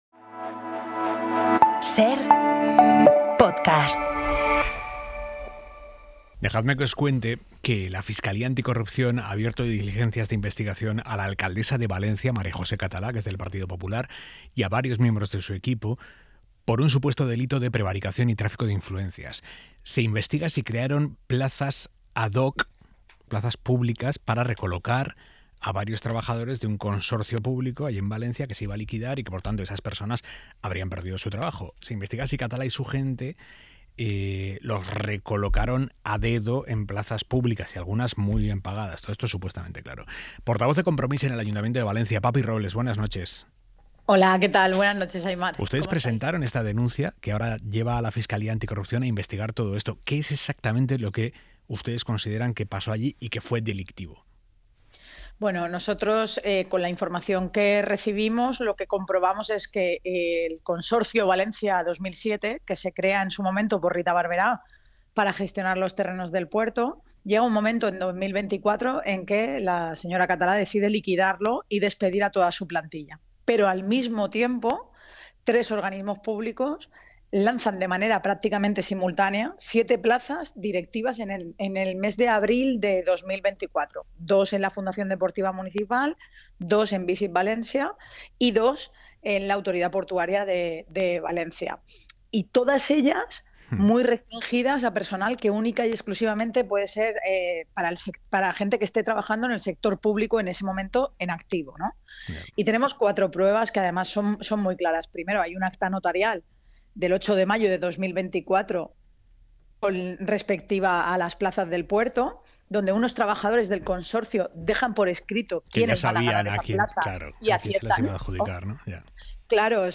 Aimar Bretos entrevista a Papi Robles, portavoz de Compromís en el ayuntamiento de Valencia